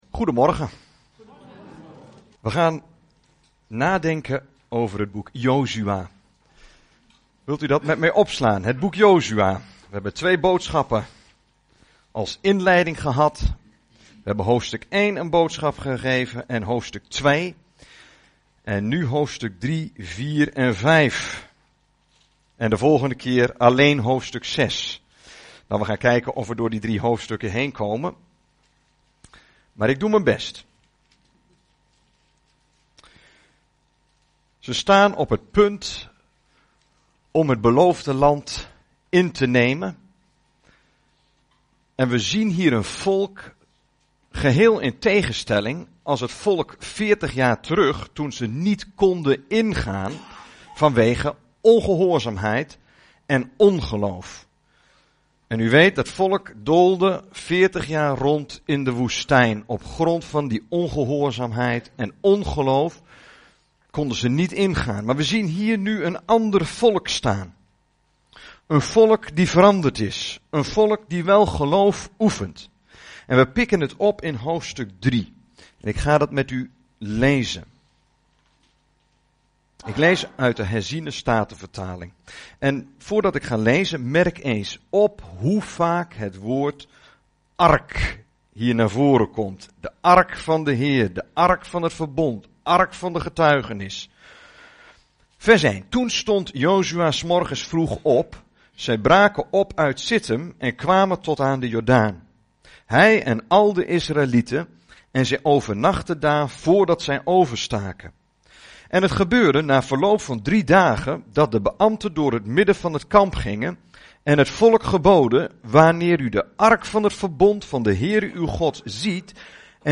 Over deze preek